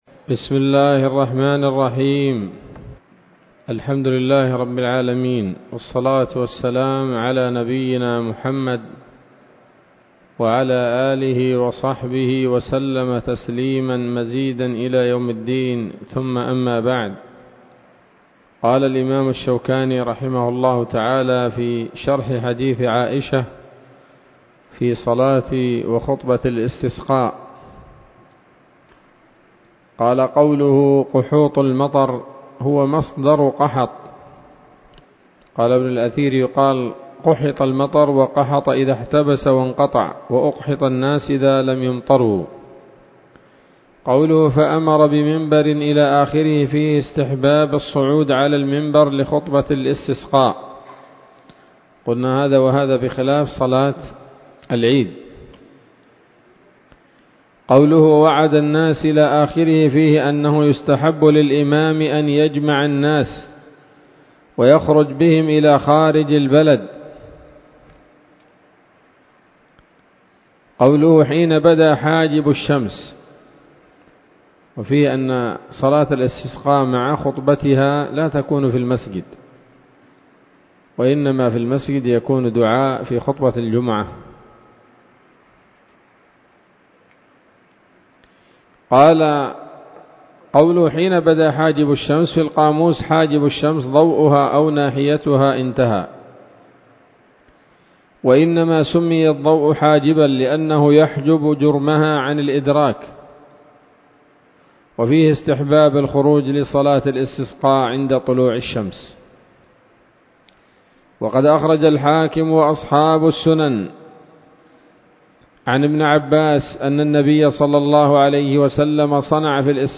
الدرس الثاني من ‌‌‌‌كتاب الاستسقاء من نيل الأوطار